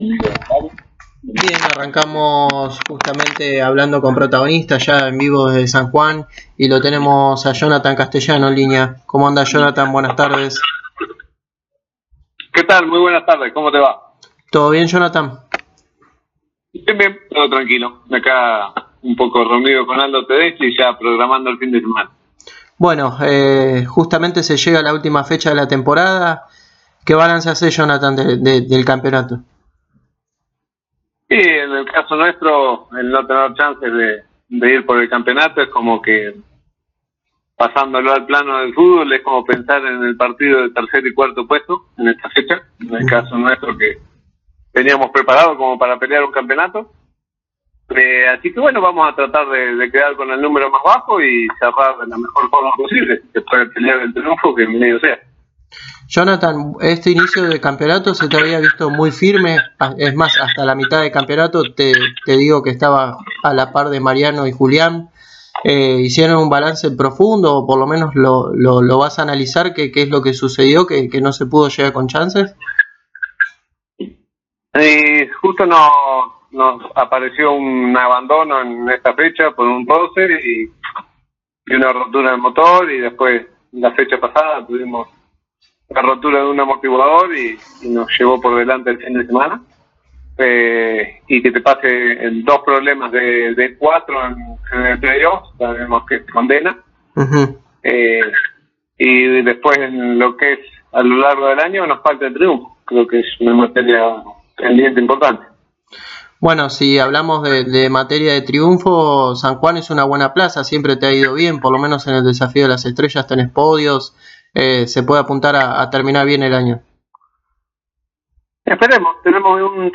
El piloto de Lobería pasó por los micrófonos de Pole Position y habló en la previa a la ultima fecha de la temporada en San Juan, donde hizo un balance de la temporada y dio su punto de pista sobre la presentación de los autos de nueva generación, donde mencionó la posibilidad de estar en 2025 con un Ford Mustang.